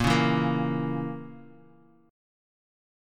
A#dim chord